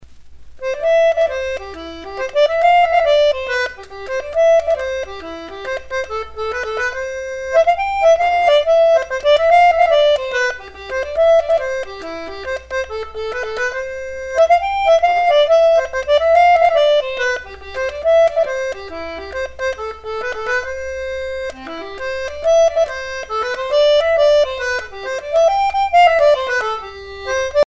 danse : hornpipe
Pièce musicale éditée